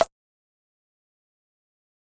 PH - TLC (Snare).wav